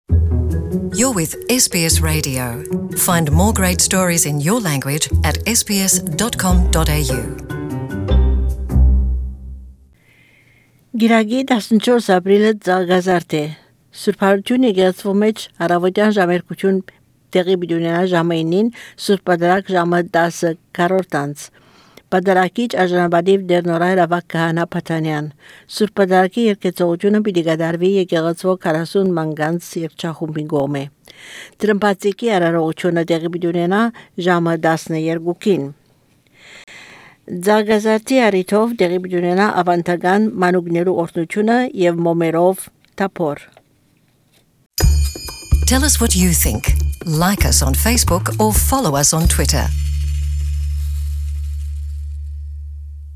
Palm Sunday Church service and blessing of children at Holy Resurrection Church.